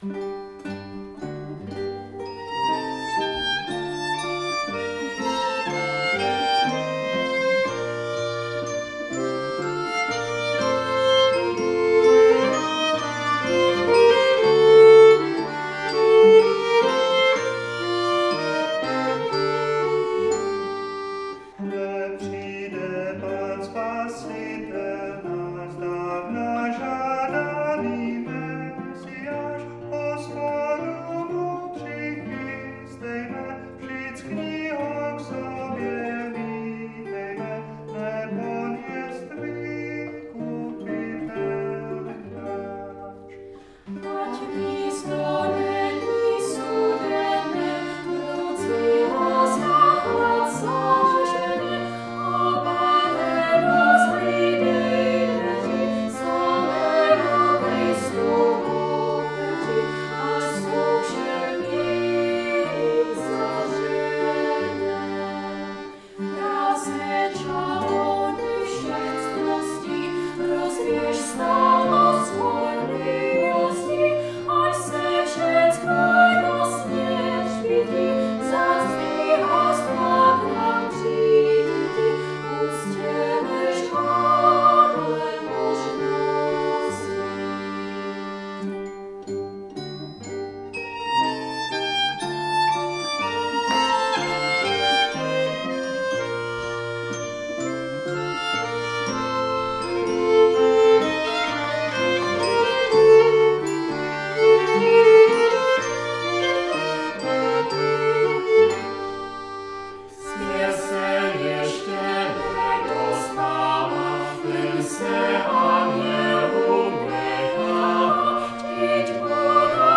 nahrávka z koncertu